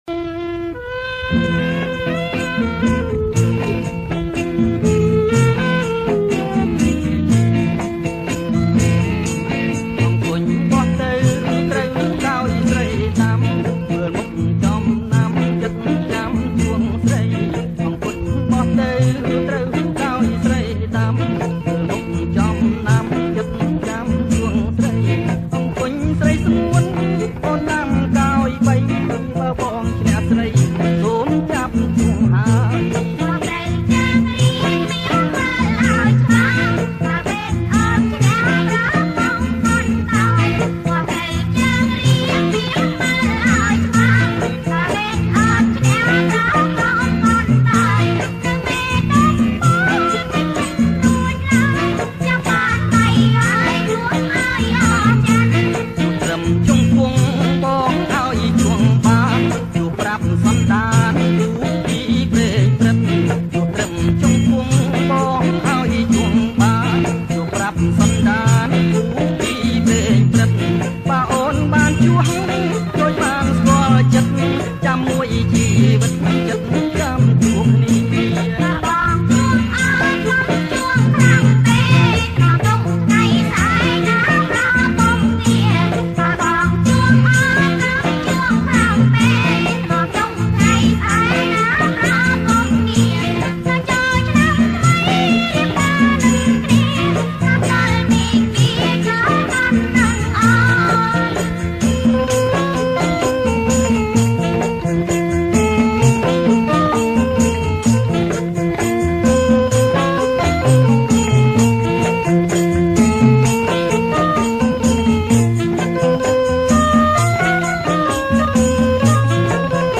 • ប្រគំជាចង្វាក់ សារ៉ាវ៉ាន់
ប្រគំជាចង្វាក់ រាំវង់